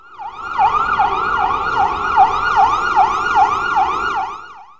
ambulancelip36.wav